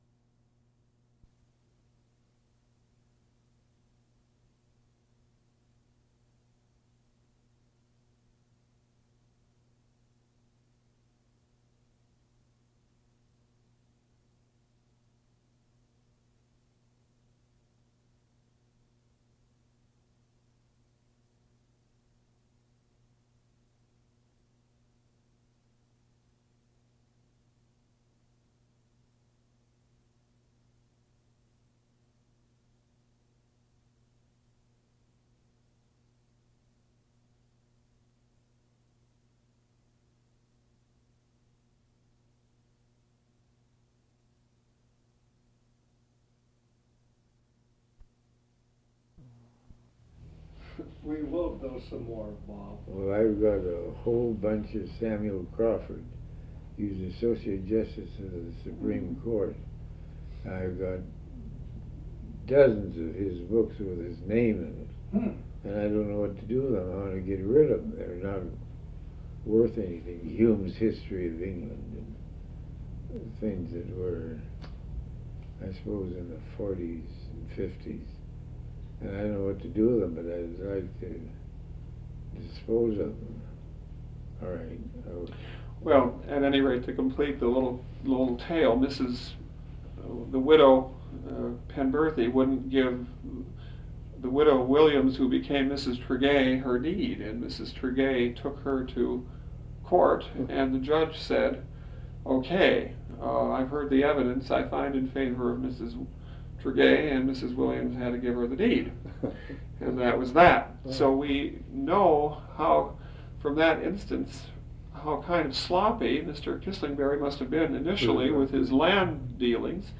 Pendarvis Interview - Part II · Mineral Point Library Archives